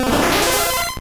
Cri de Staross dans Pokémon Or et Argent.